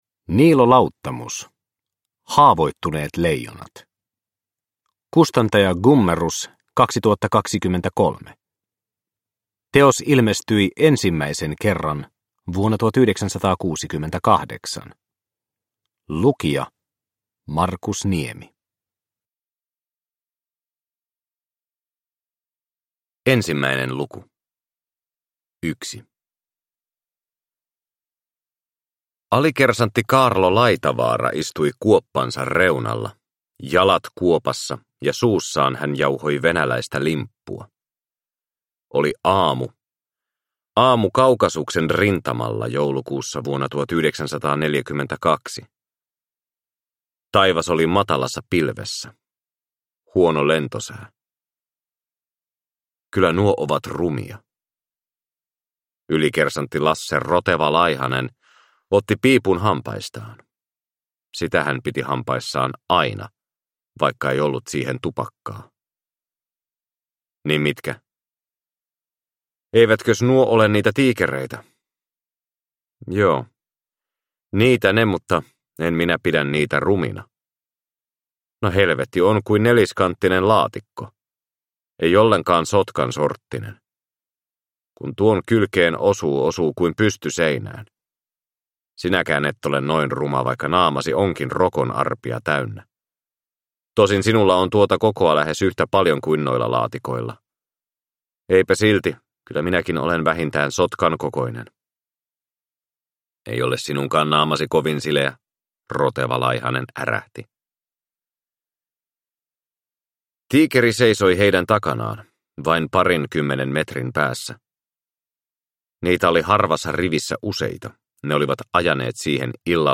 Haavoittuneet leijonat – Ljudbok – Laddas ner